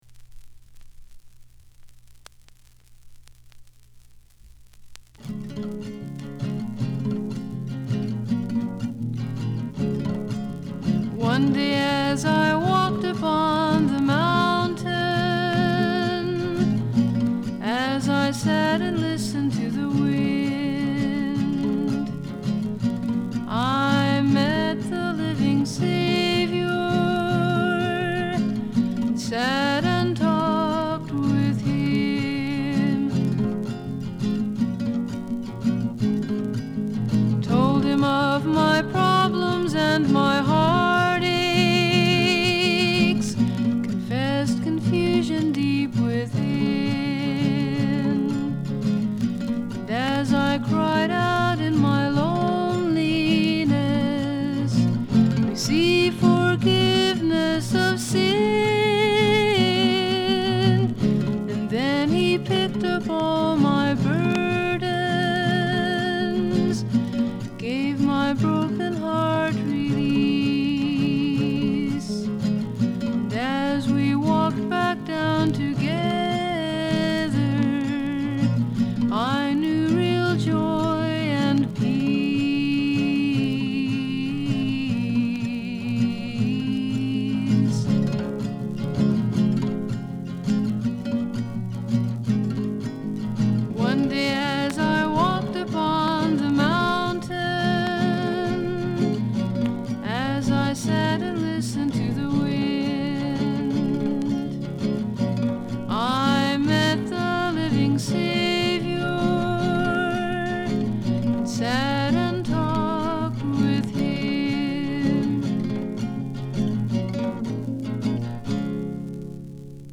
original xian folk-pop. Outstanding sparse production